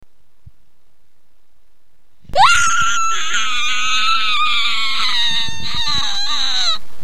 Download Scary Scream sound effect for free.
Scary Scream